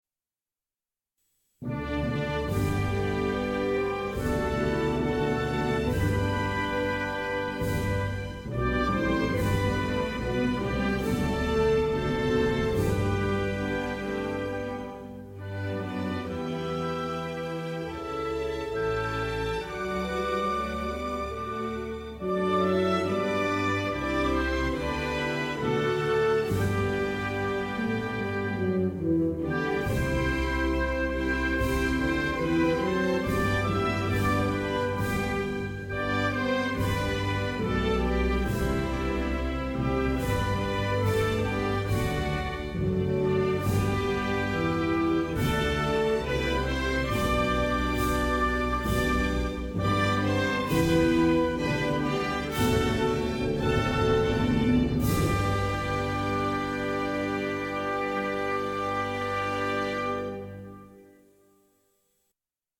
National_Anthem_of_Zimbabwe.mp3